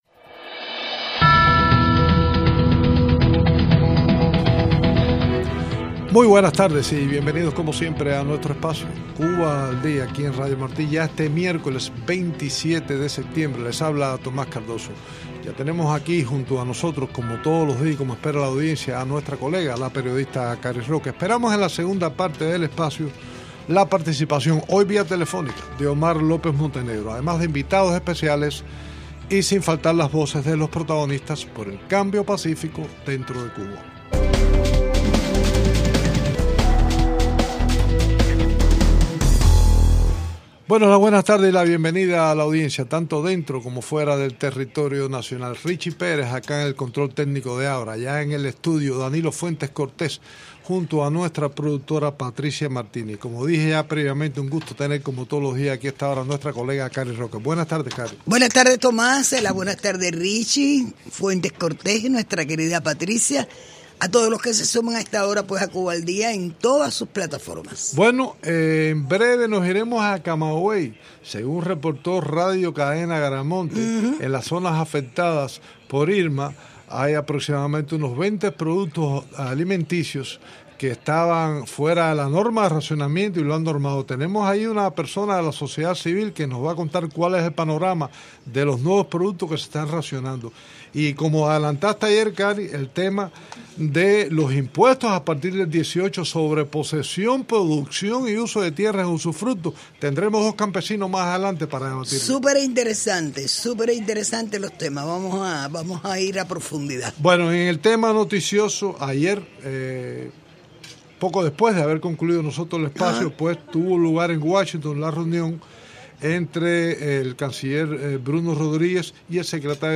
seguidamente dos agricultores nos hablaron de la nueva medida de cobrar impuestos a las personas que tienen tierras en usufructo en Cuba.